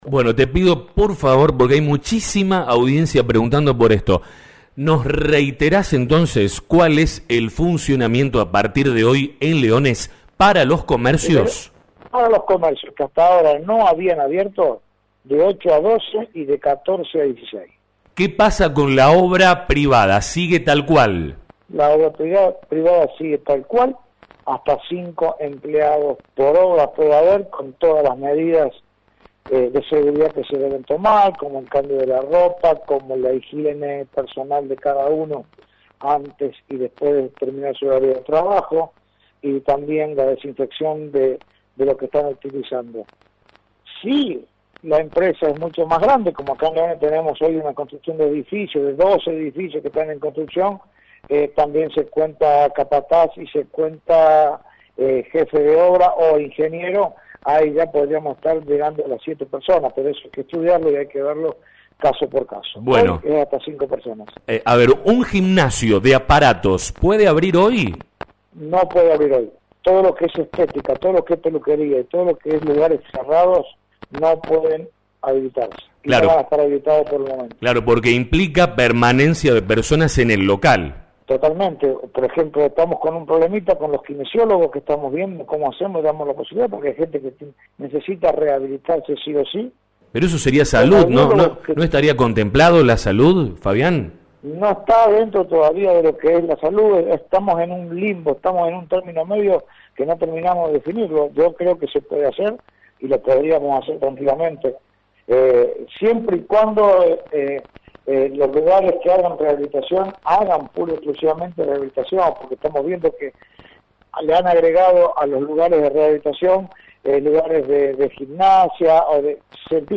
En diálogo con La Urbana, el intendente Fabián Francioni, habló sobre el particular: